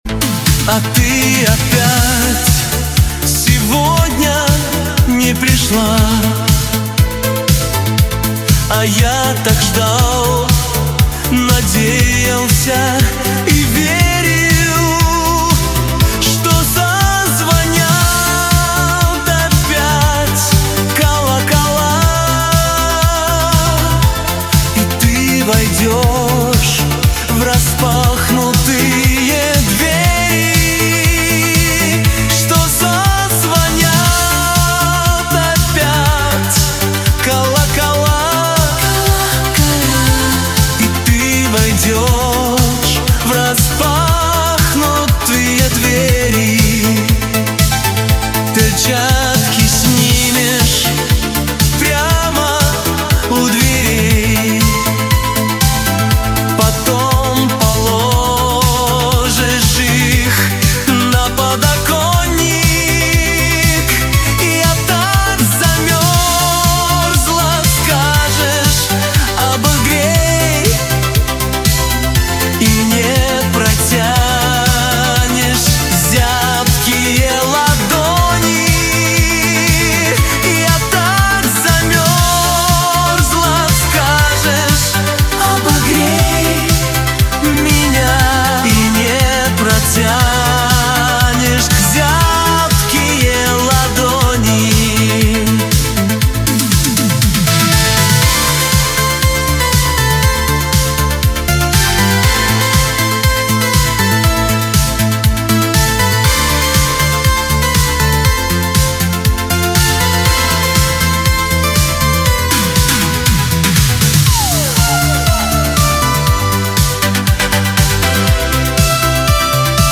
Качество: 320 kbps, stereo
Нейросеть песни 2026, Песни Суно ИИ